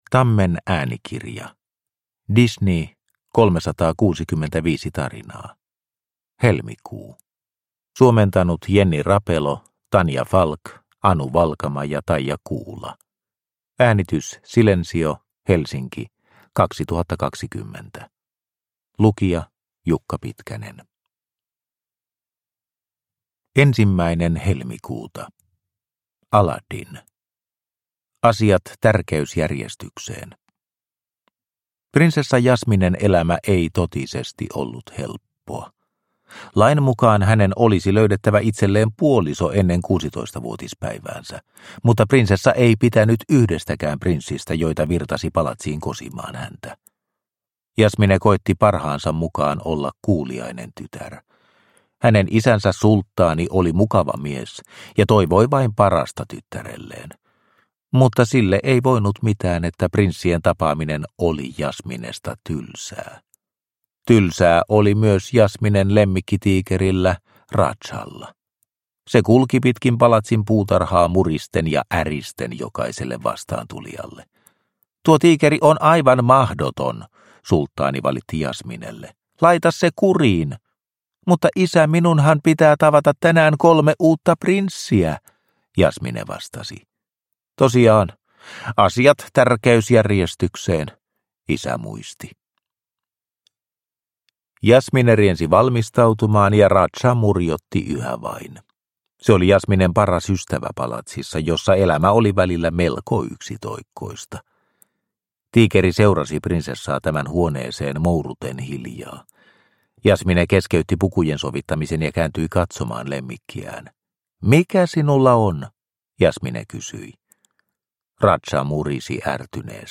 Disney 365 tarinaa, Helmikuu – Ljudbok – Laddas ner